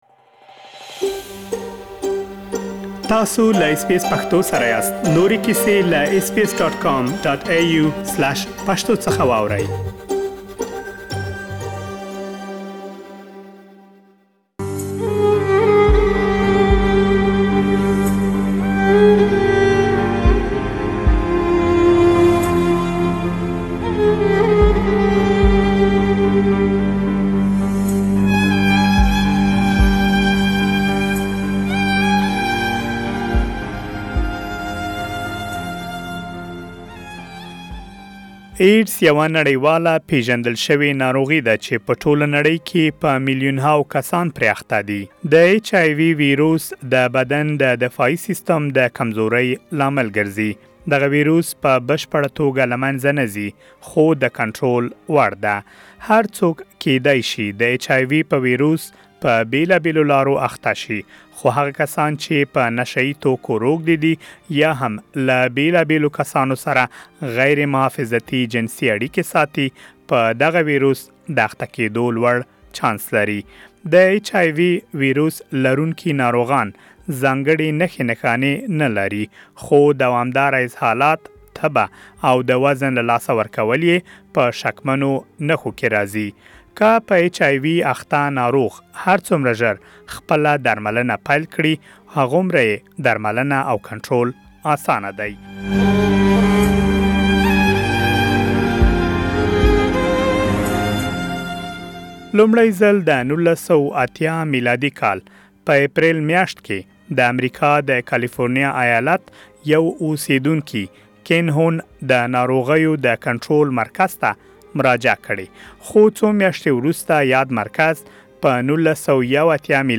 For more details, Please, listen to the full report in Pashto.